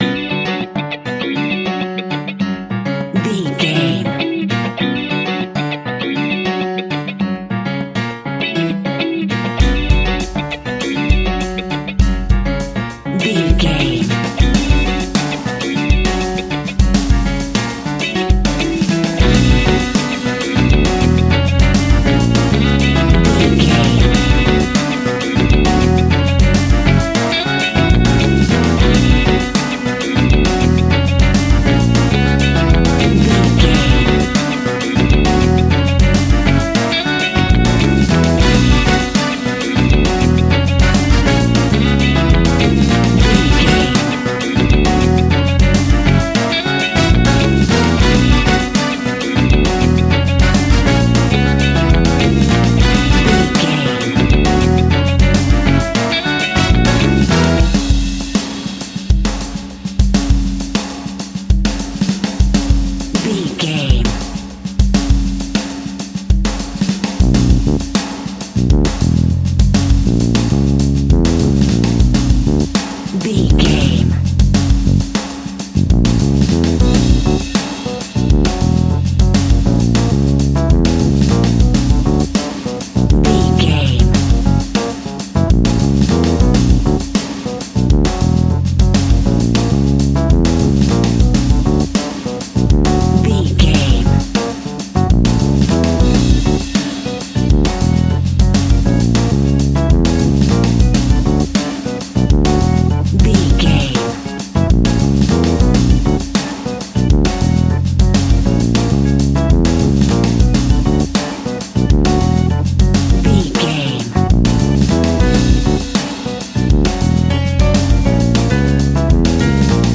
Aeolian/Minor
flamenco
salsa
cuban music
uptempo
bass guitar
brass
saxophone
trumpet
fender rhodes
clavinet